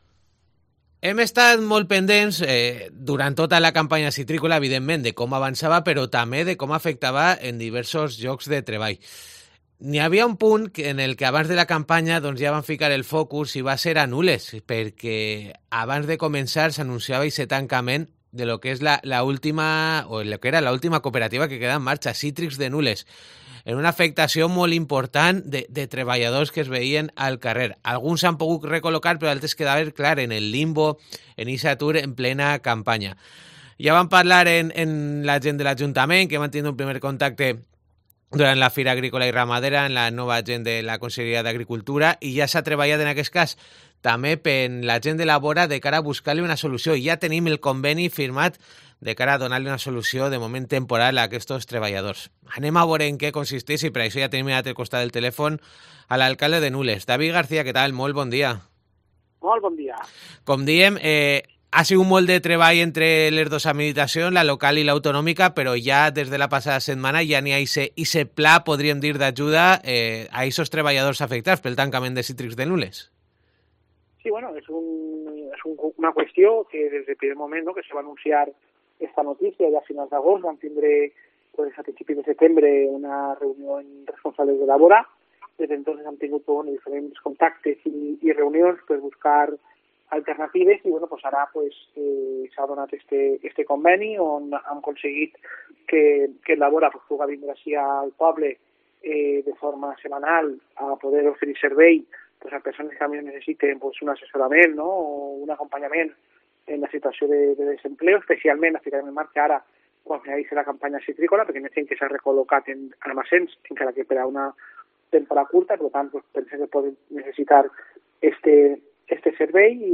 El alcalde de Nules valora el pacto con Labora para los afectados por el cierre de Cítrics de Nules